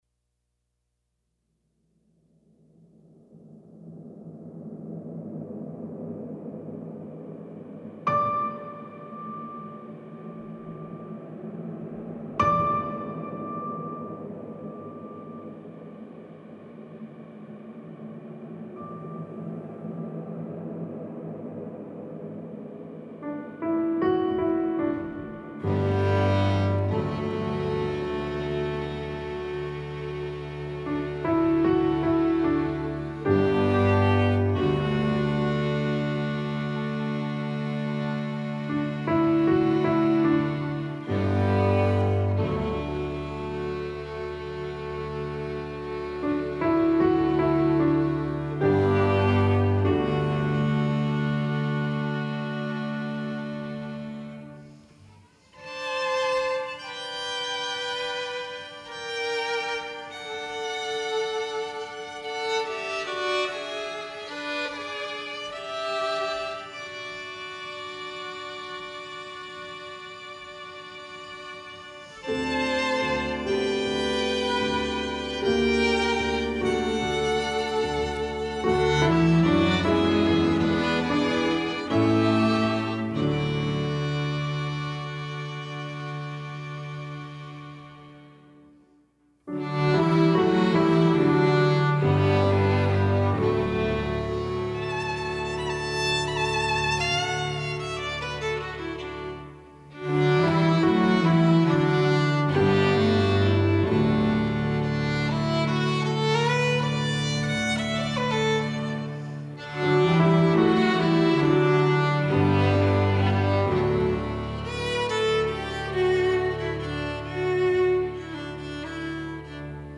At Tank Recording Studio